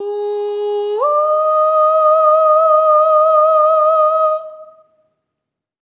SOP5TH G#4-R.wav